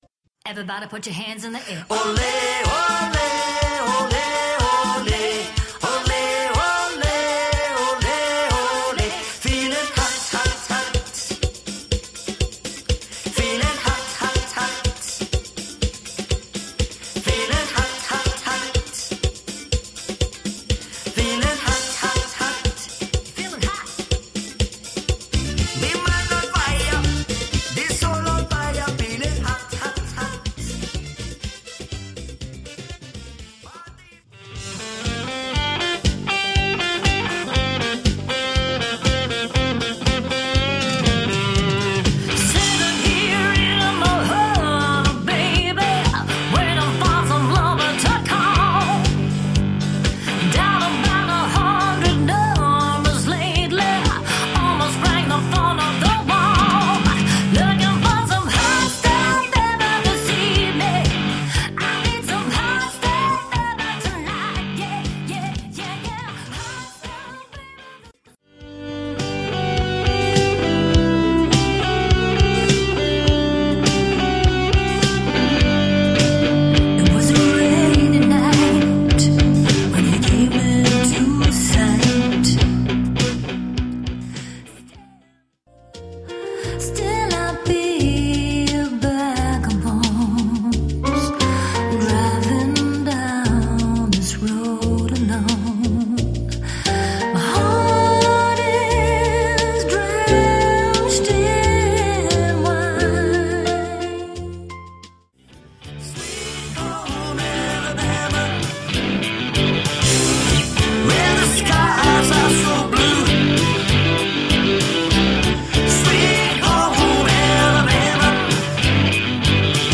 are a dynamic and versatile musical duo based in Perth
Their repertoire spans every genre of music from the 40s to the naughties – including Retro, Funk, Reggae, R&B. latin, 70s and 80s Pop, show-stopping ballads, Classic rock with soaring guitar solo’s and original works of passion.